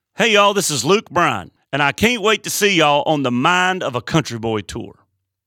LINER Luke Bryan (can't wait to see you on the Mind of a Country Boy Tour)